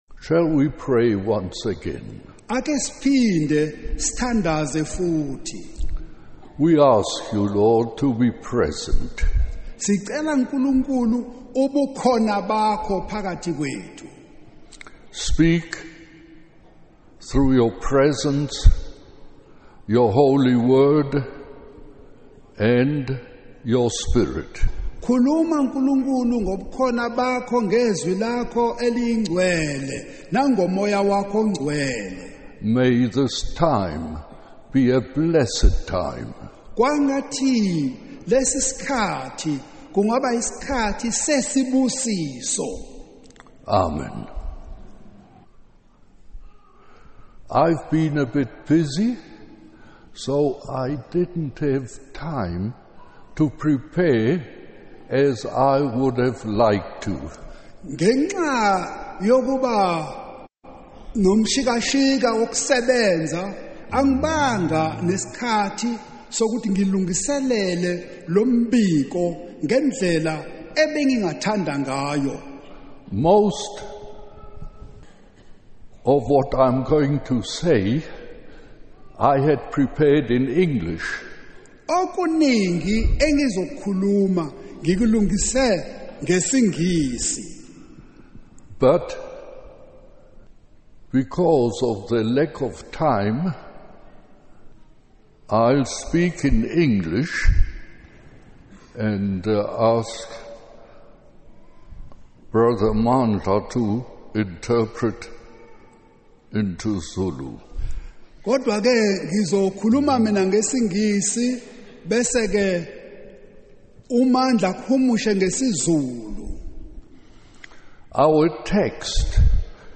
In this sermon, the preacher emphasizes the importance of living a life that reflects the teachings of Jesus Christ. He urges listeners to examine their lives and ensure that they are living wisely, not foolishly.